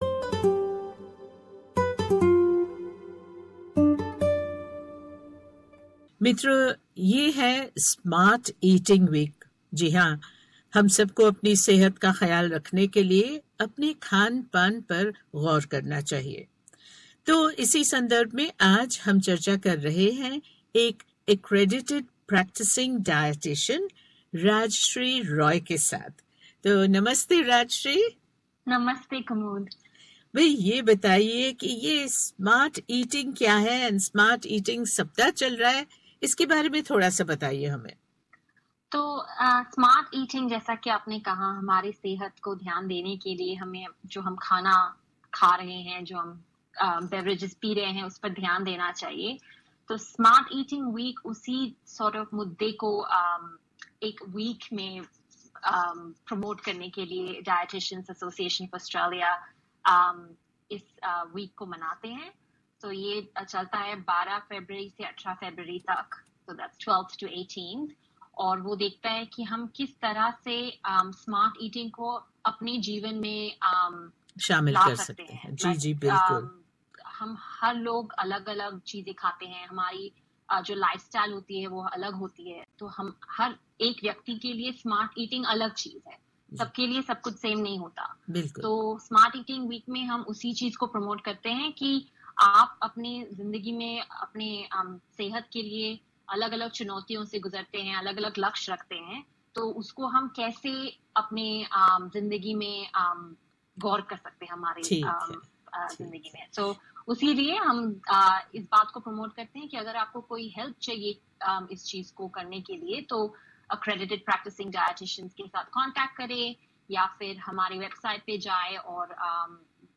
Tune in to hear the interview and remember do eat but eat smartly!